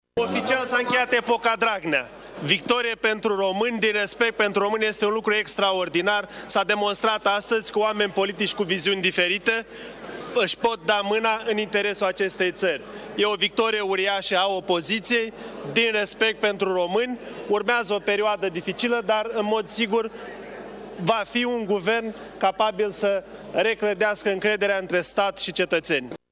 Oficial s-a încheiat epoca Dragnea, a declarat după încheierea numărătorii liderul PMP, Eugen Tomac: